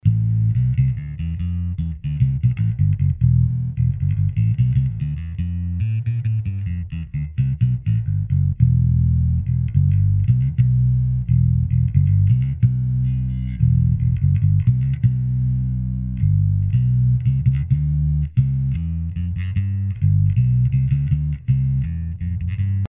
Jsou to niklovky, hrají pěkně, mají cinkavý a ostrý zvuk.
natáhl je, naladil, a hraje to docela pěkně: